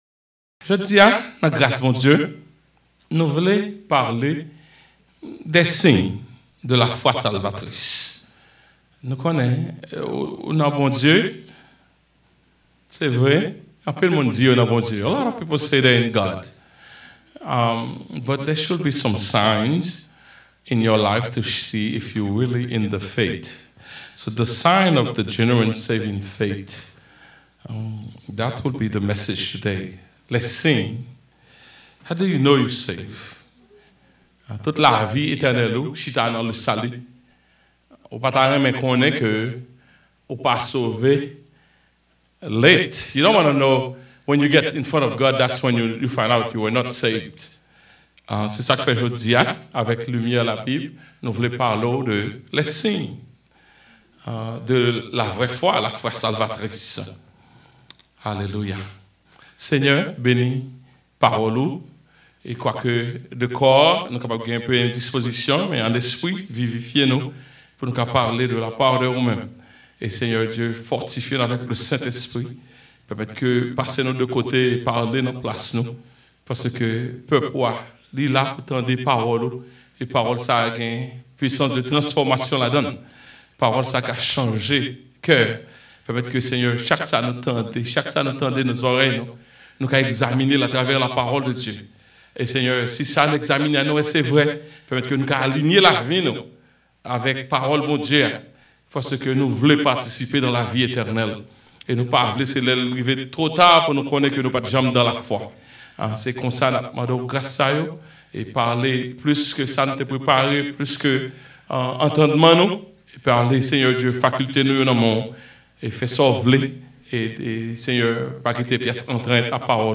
CLICK HERE TO DOWNLOAD THE SERMON: LES SIGNES DE LA FOI SALVATRICE (SERMON)
signs-of-genuine-christian-faith-sermon-edited-.mp3